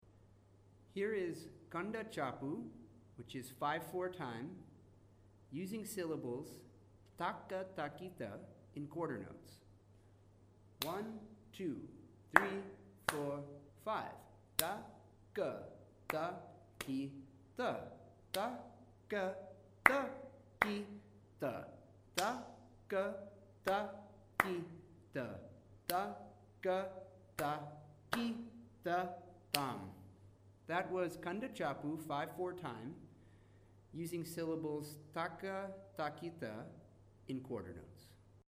It is shown through a series of claps: Clap on beat 1; Clap on beat 3; Clap on beat 4.
Each clip shows Kanda Chapu thalum while reciting Ta Ka Ta Ki Ta in different subdivisions.
Kanda Chapu with Ta Ka Ta Ki Ta in Quarter Notes
kandachapu-quarternotes.mp3